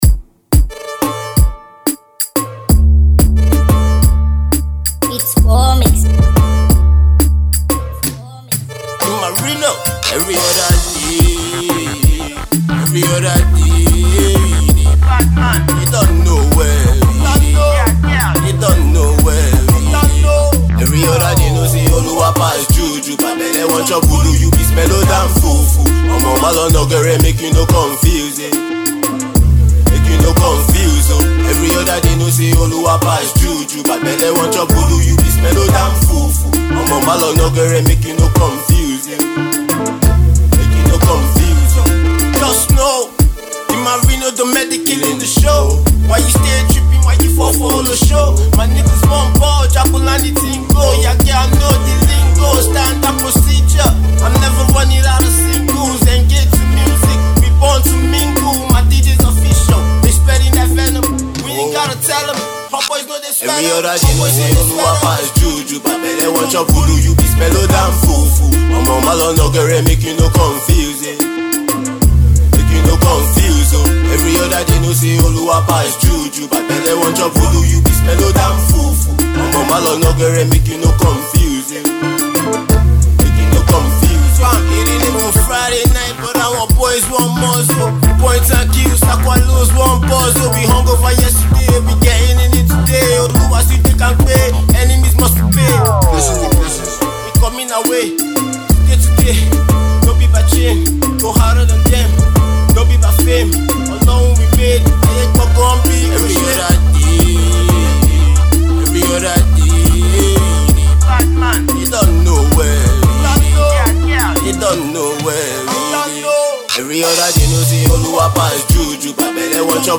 Alternative Rap
a dancehall tinged rap song